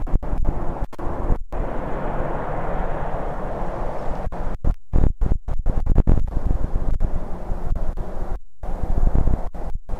2019 WILTON ENVIRONMENTAL NOISE
Vent 15/2/19 Listen